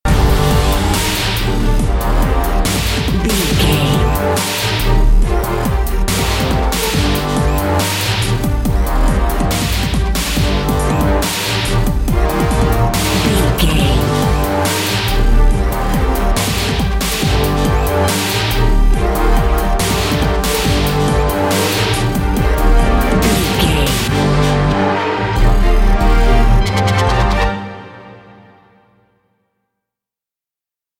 Aeolian/Minor
Fast
strings
drum machine
horns
orchestral
orchestral hybrid
dubstep
aggressive
energetic
intense
synth effects
wobbles
driving drum beat
epic